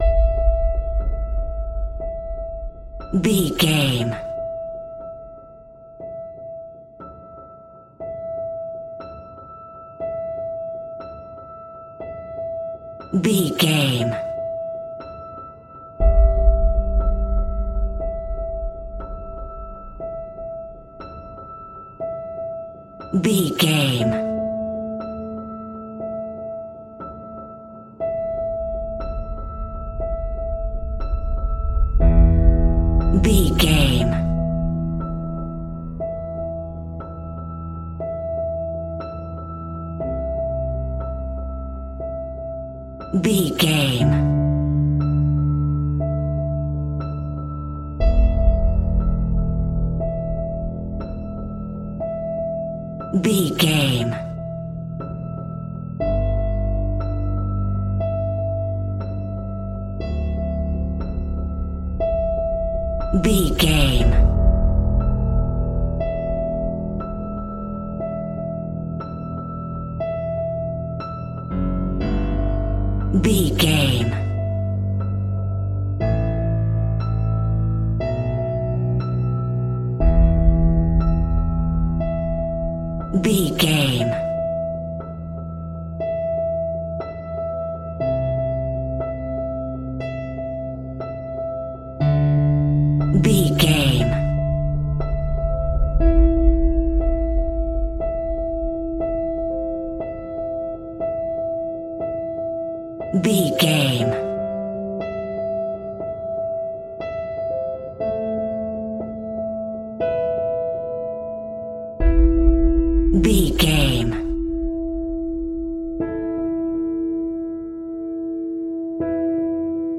Aeolian/Minor
Slow
ominous
dark
haunting
eerie
creepy
horror music